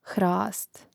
hrȃst hrast